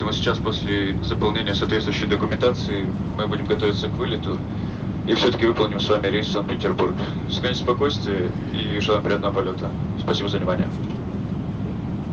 самолет